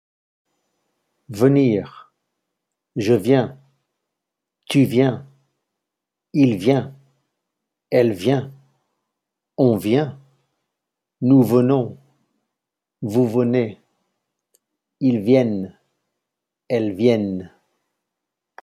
As you listen to the audio recording, notice all singular forms of venir are pronounced alike and the final consonants are all silent.